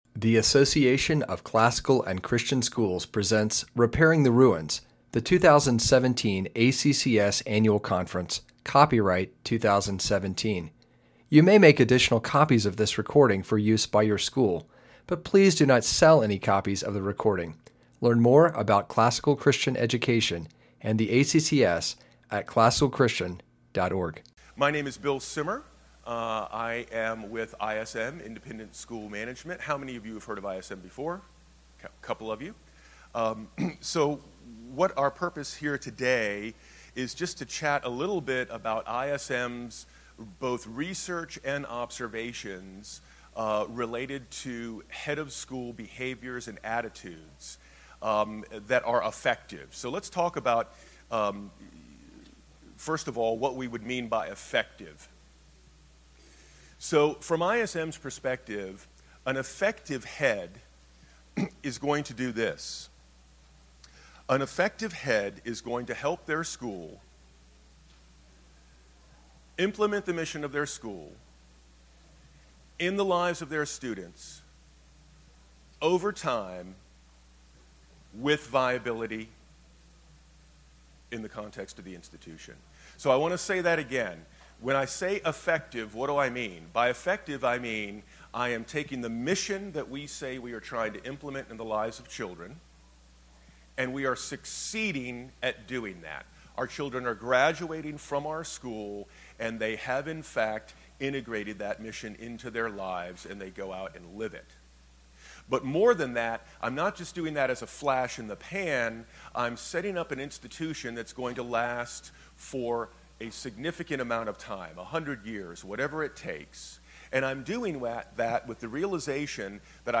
2017 Workshop Talk | 1:07:07 | All Grade Levels, Leadership & Strategic